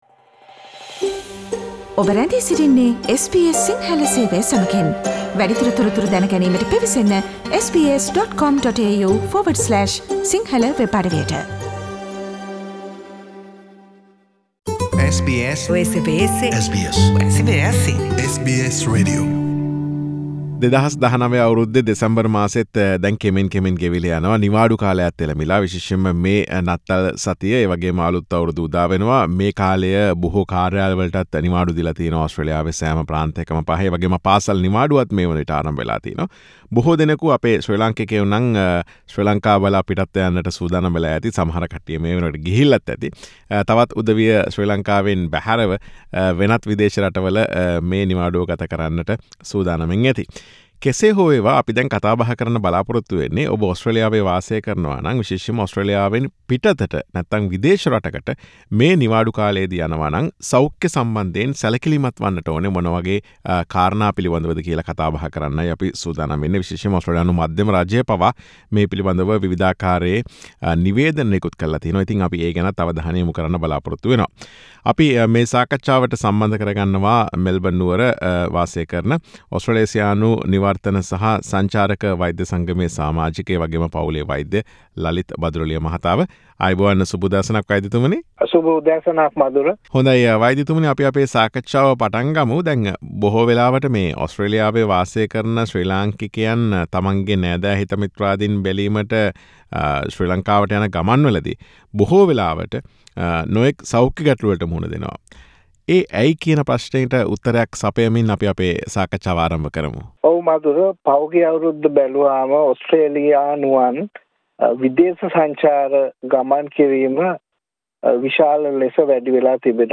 සාකච්ඡාව.